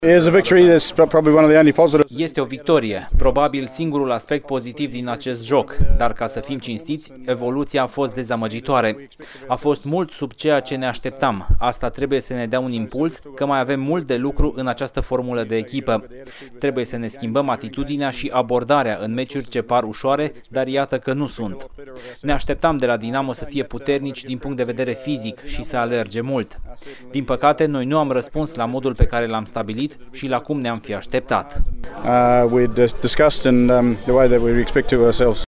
Iată şi declaraţiile antrenorilor